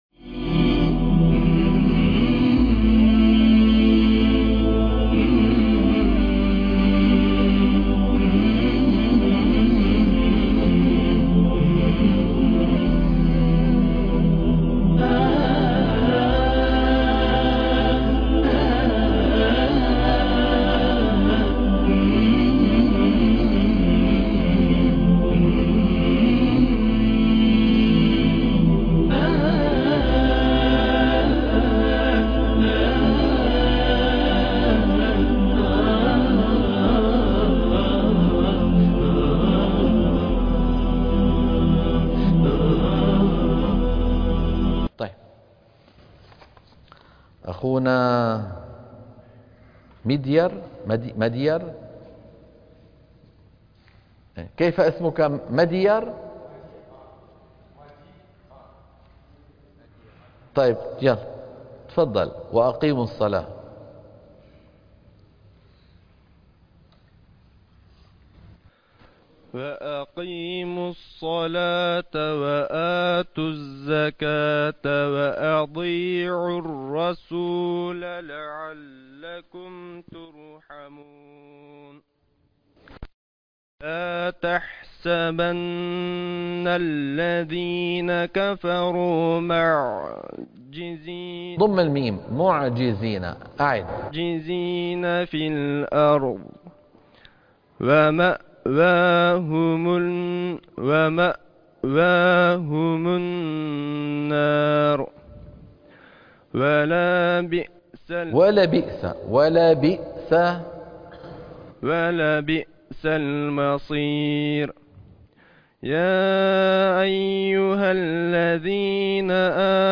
برنامج تصحيح التلاوة الحلقة - 108 - تصحيح التلاوة من الصفحة 358 إلى 361 - الشيخ أيمن سويد